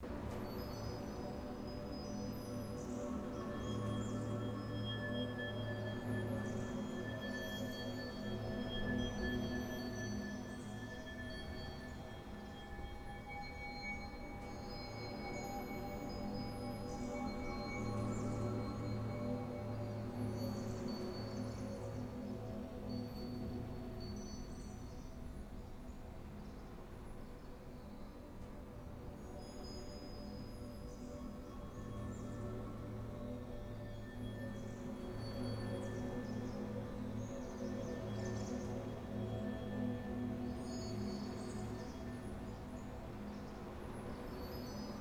sfx-pm-hub-amb-03.ogg